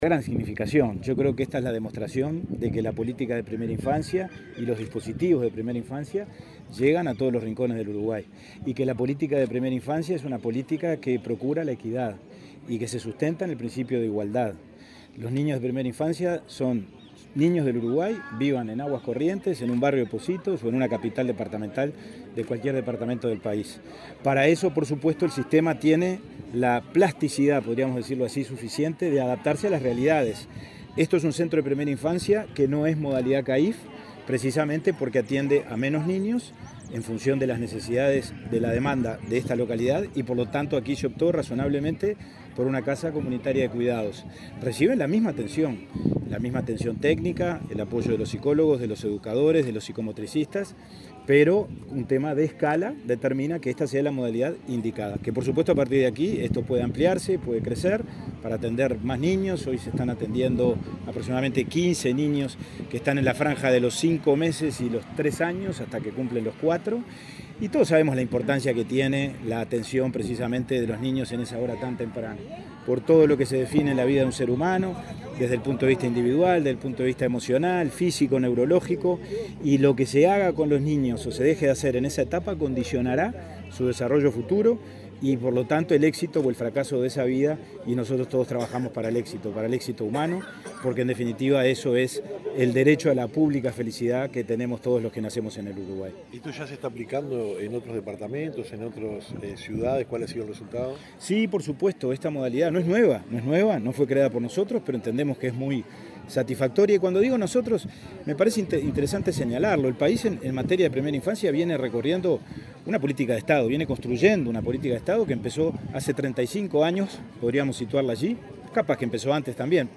Declaraciones a la prensa del presidente de INAU, Pablo Abdala
Luego, el presidente de INAU, Pablo Abdala, dialogó con la prensa.